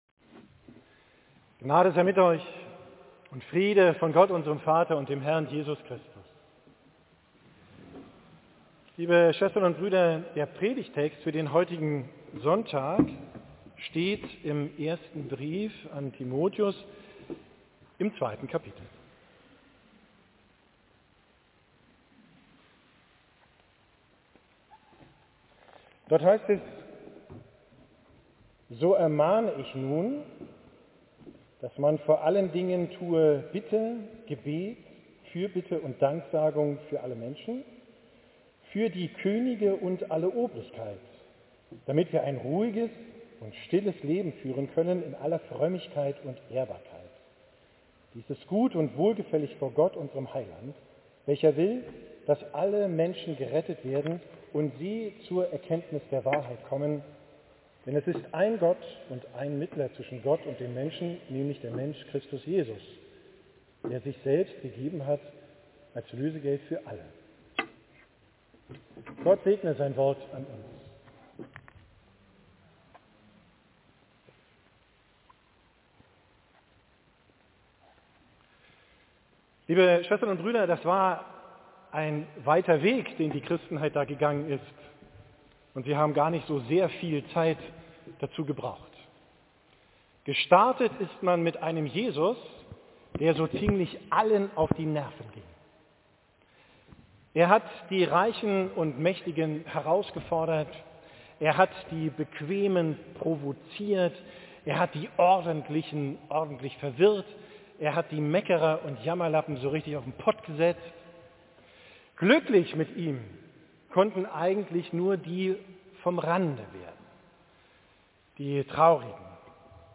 Predigt vom Sonntag Rogate, 14.